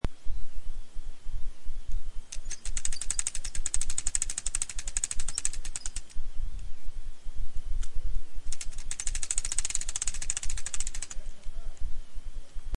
Download Fishing sound effect for free.